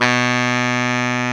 Index of /90_sSampleCDs/Roland LCDP06 Brass Sections/BRS_Pop Section/BRS_Pop Section1
SAX B.SAX 14.wav